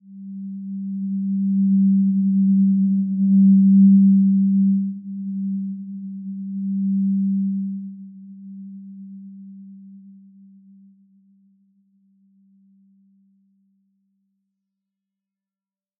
Simple-Glow-G3-mf.wav